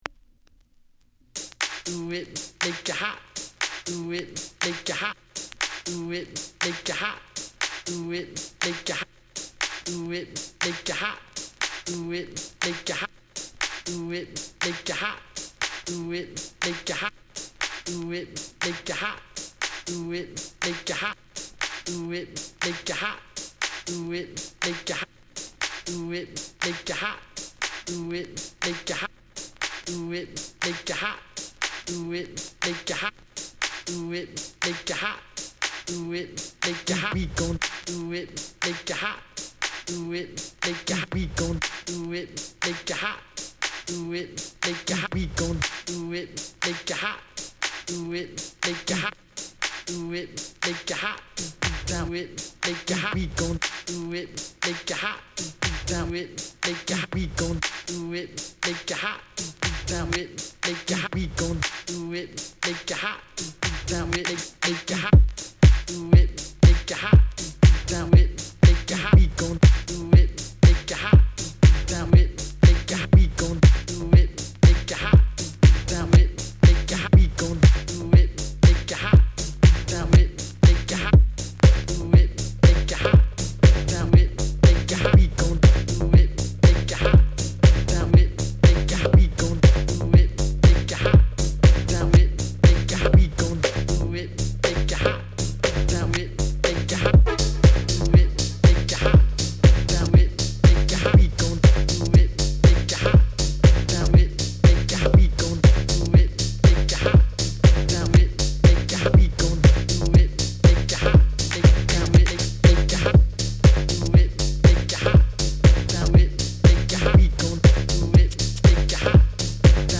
House / Techno
頭から尻尾まで執拗なヴォイスサンプルと剥き出しのリズム、シンプルなシンセワークの抜き差しでRAW & LOWにグルーヴ。